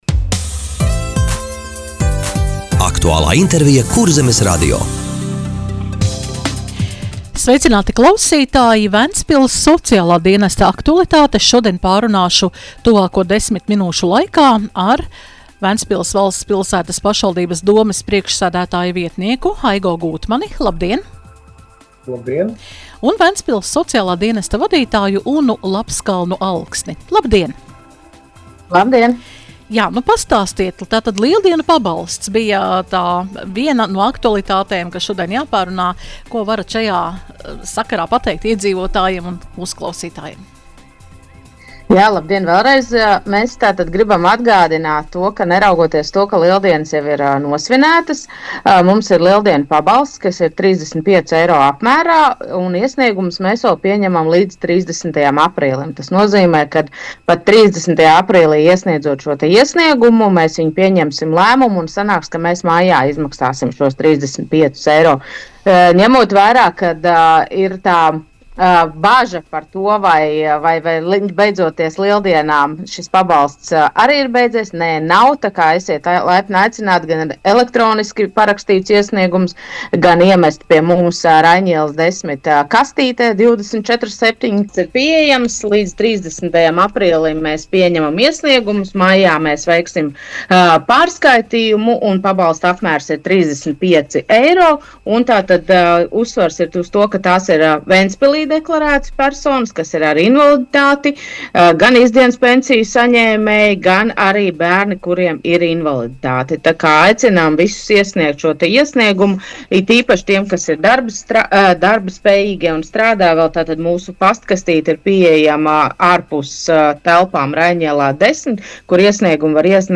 Radio saruna Ventspils Sociālā dienesta aktualitātes - Ventspils